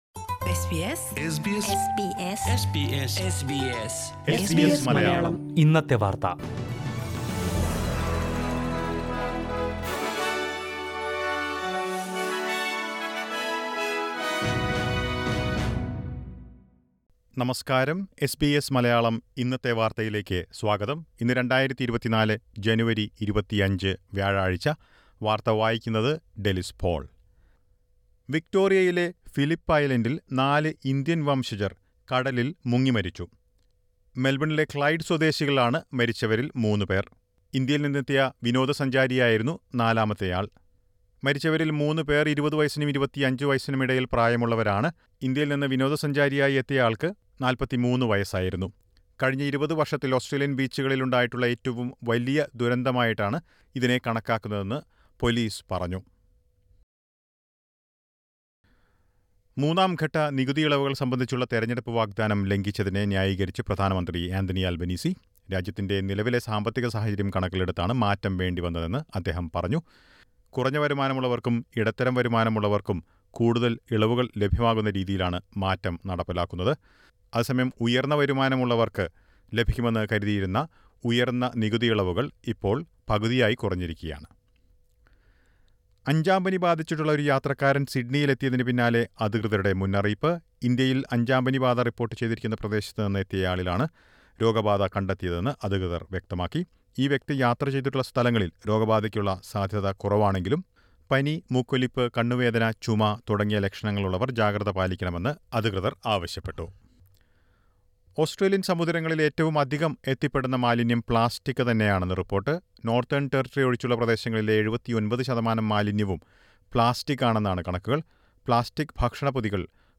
2024 ജനുവരി 25ലെ ഓസ്‌ട്രേലിയയിലെ ഏറ്റവും പ്രധാനപ്പെട്ട വാര്‍ത്തകള്‍ കേള്‍ക്കാം.